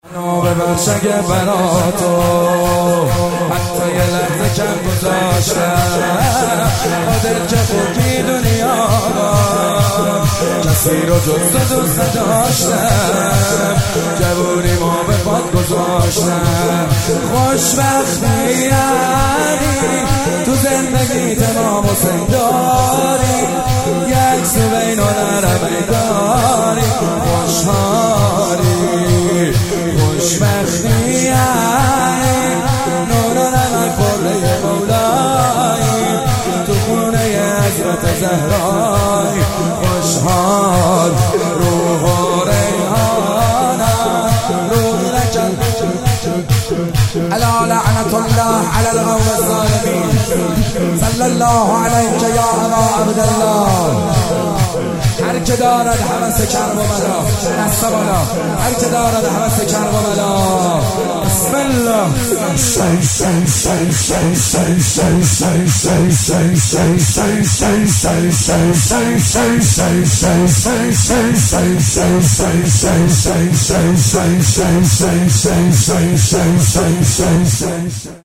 مولودی به مناسبت ولادت امام زمان(عج)
کربلایی محمدحسین حدادیان